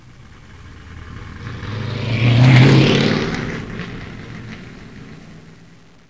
Auto da corsa
Veicolo a motore, auto che passa veloce.